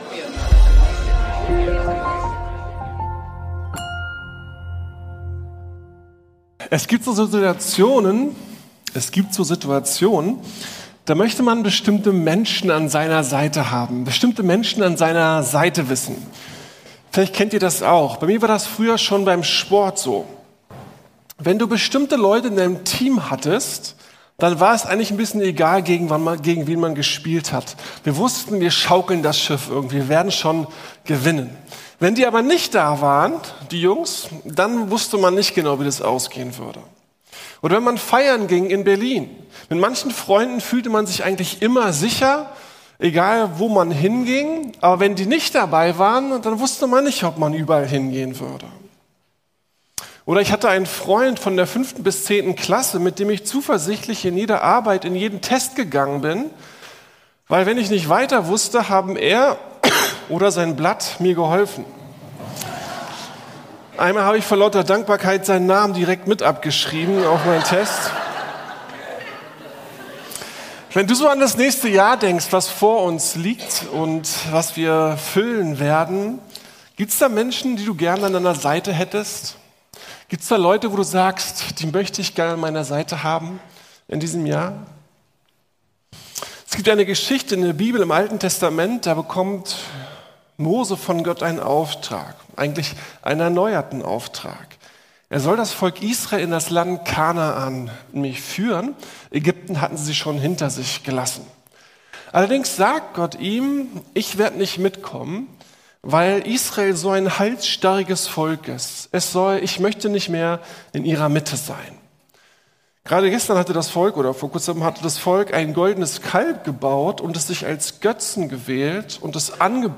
Wir feiern Gottes Gegenwart ~ Predigten der LUKAS GEMEINDE Podcast